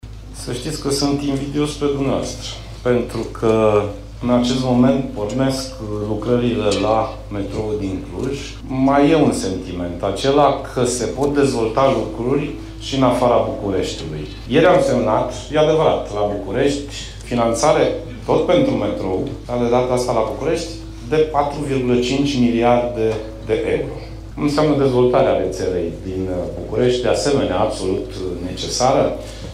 Ministrul Transporturilor – Sorin Grindeanu: „Se pot dezvolta lucruri și în afara Bucureștiului”
05iun-16-Grindeanu-despre-metrou.mp3